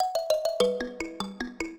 mbira